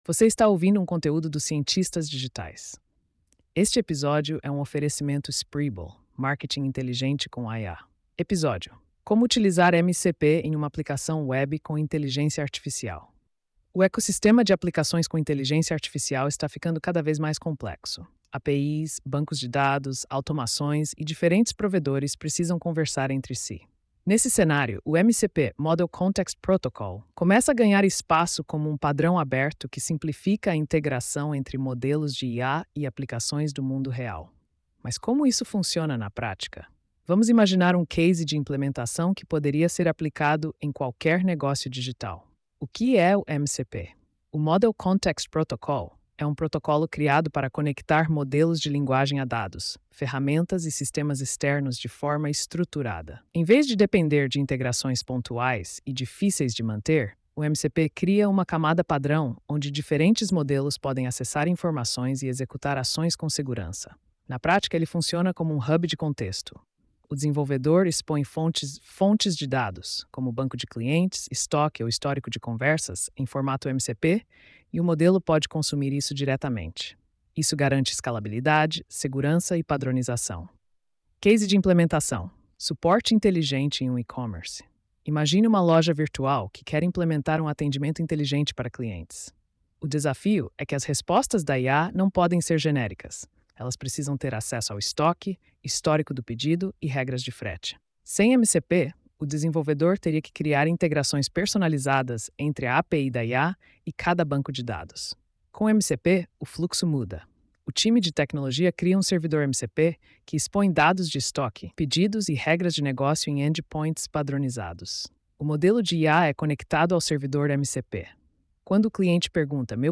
post-4391-tts.mp3